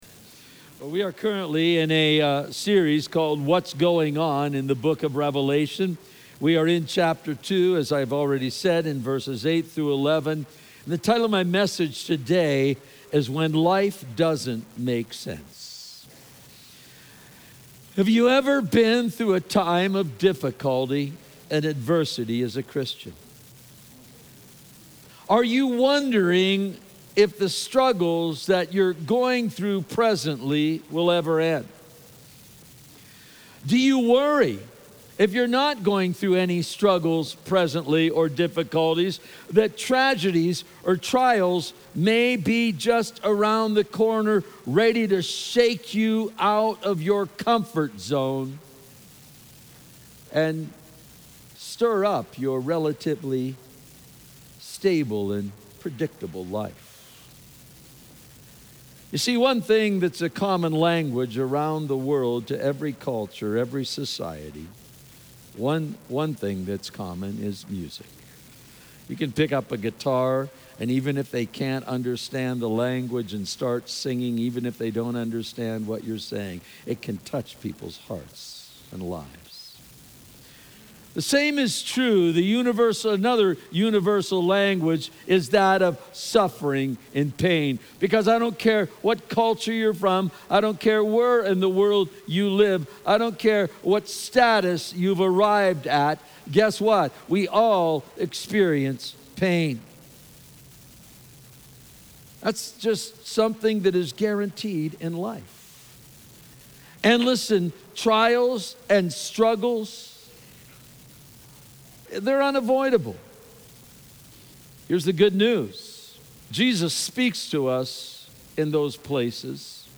Sermon Details Calvary Chapel High Desert